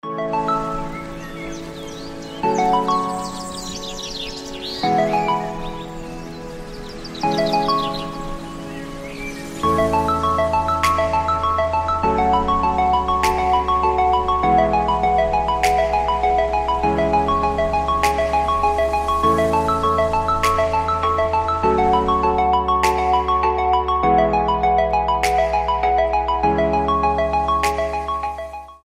• Качество: 320, Stereo
мелодичные
без слов
Пение птиц